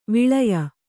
♪ viḷaya